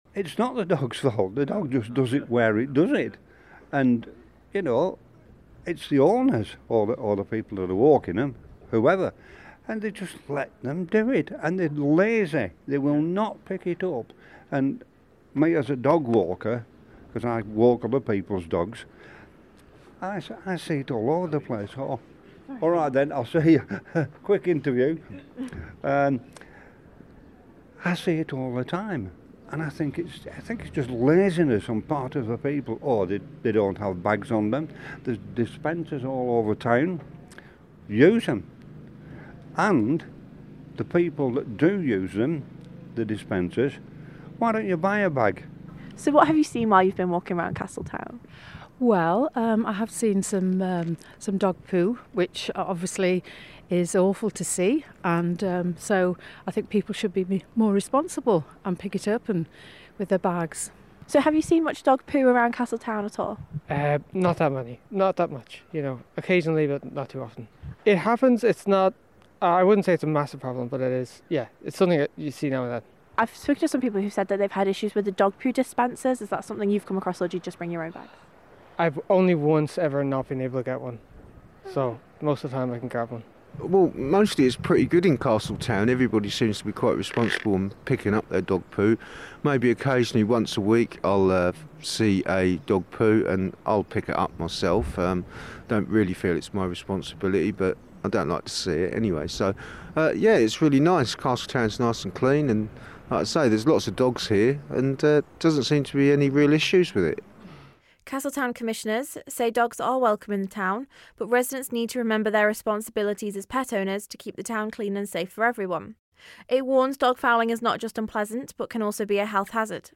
has been speaking to residents and investigating the situation: